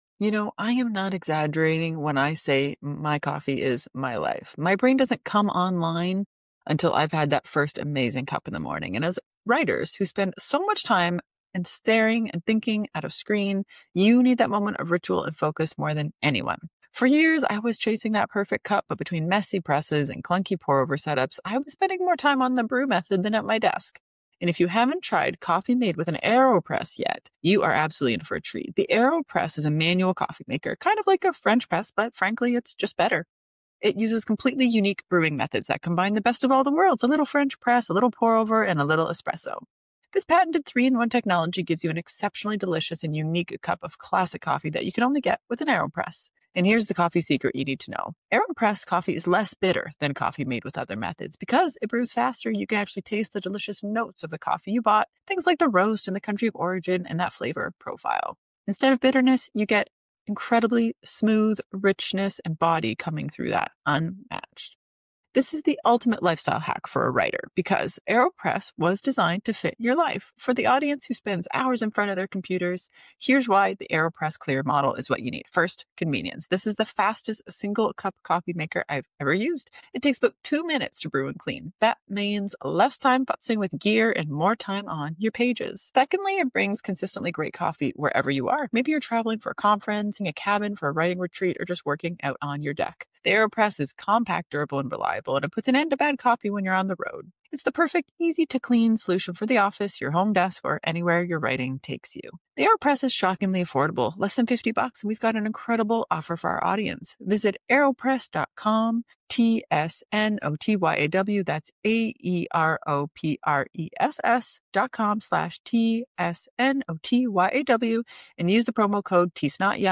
Expect good advice, honest insights, and a few laughs along the way.